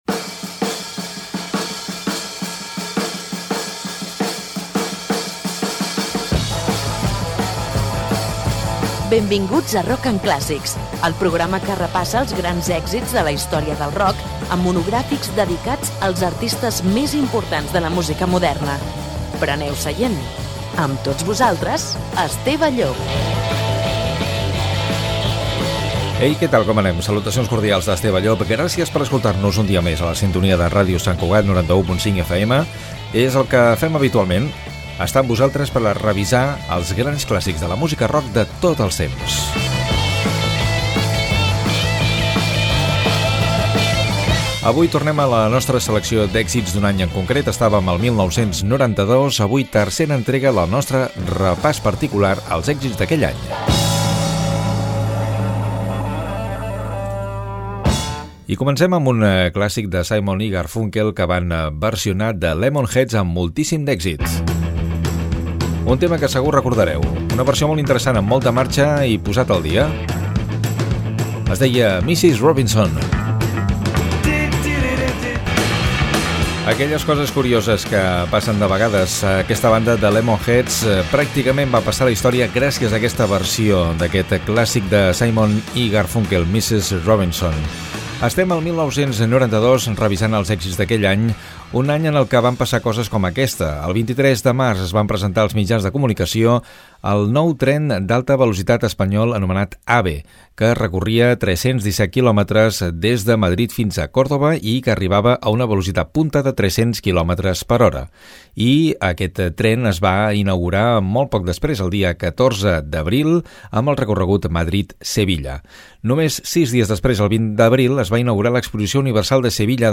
Careta del programa, identificació de l'emissora, presentació del programa, introducció de temes musicals, efemèrides de l'any 1992.
FM